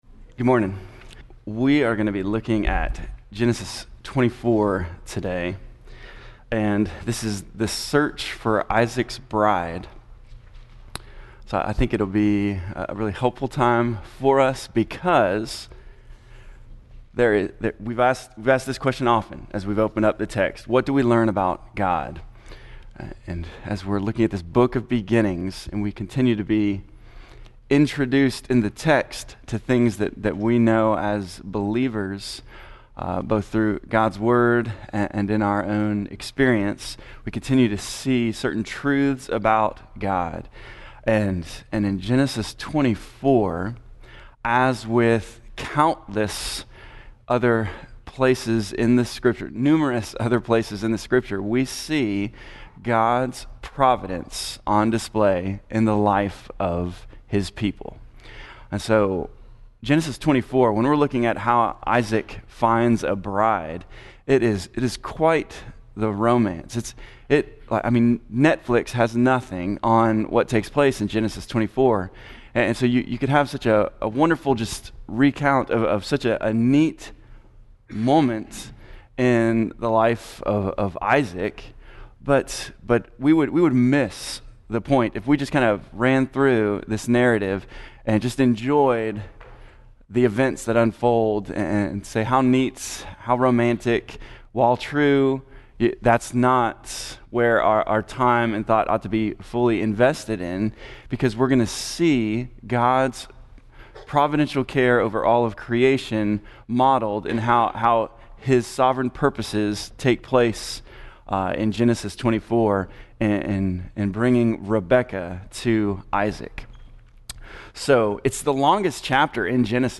Lesson 34 in the Genesis: Foundations Sunday School class.